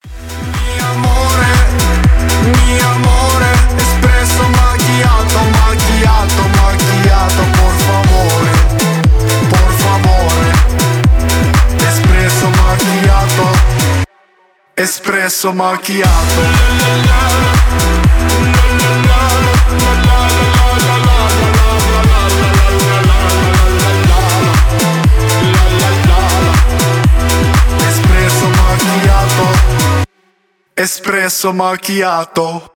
танцевальные
электро-свинг